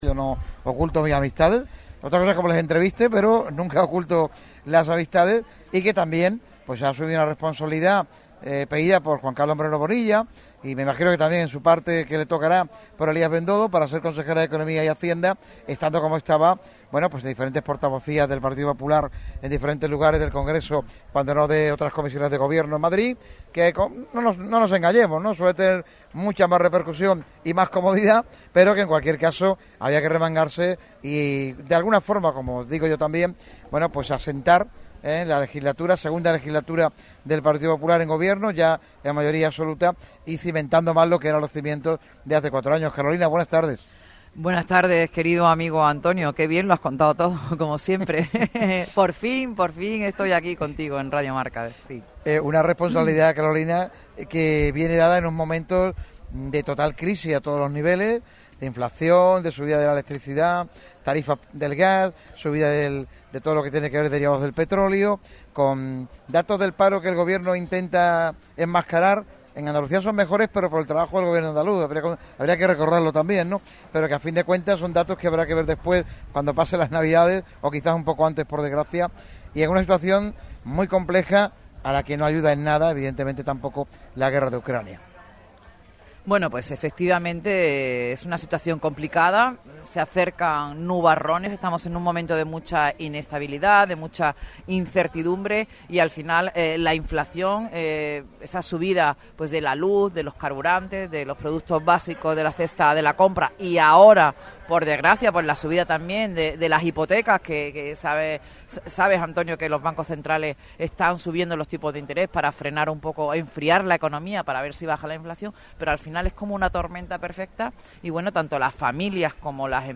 Carolina España, consejera de Economía y Hacienda de la Junta de Andalucía, pasa por el micrófono rojo de Radio Marca Málaga en un programa especial
en El Balneario, rincón emblemático de los Baños del Carmen.